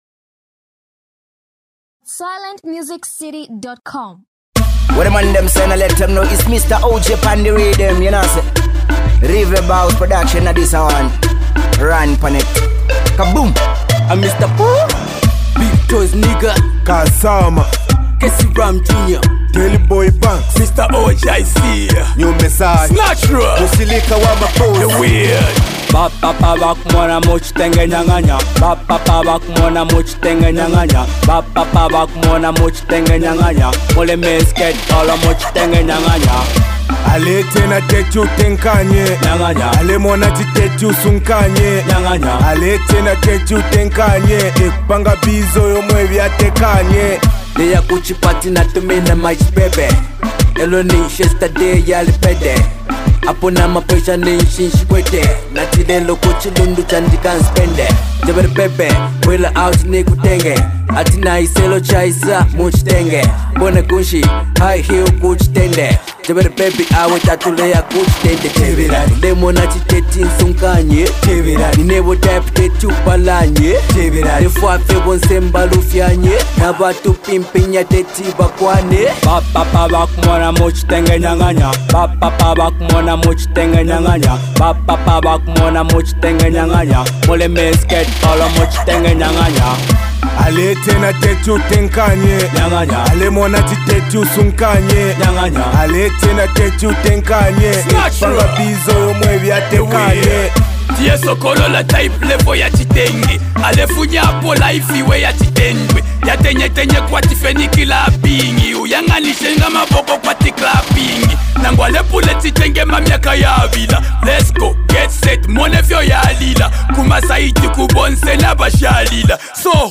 New dancehall king, posted by.